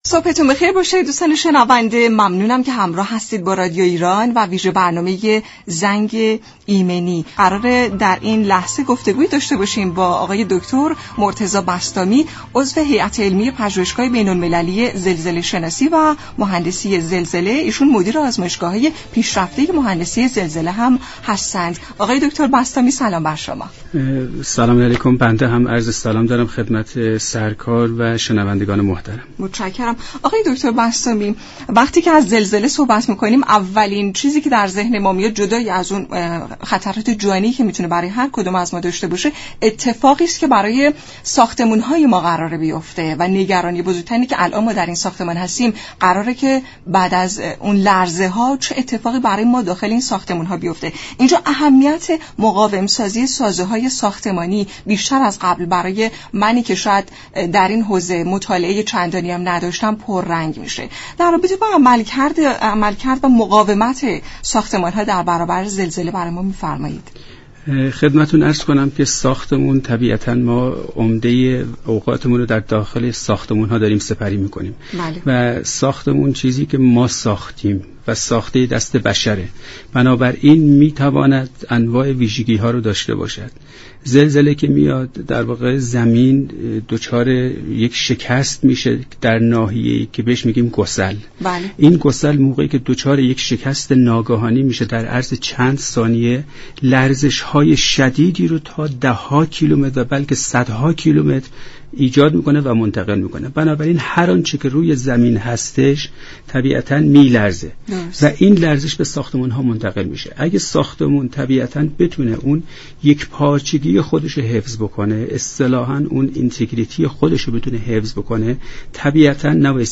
عضو هیات علمی پژوهشگاه زلزله شناسی و مهندسی زلزله در گفت و گو با رادیو ایران گفت: ساختمان ها باید اجزای مناسبی داشته باشند و هر یك از اجزا كار خود را به درستی انجام دهند.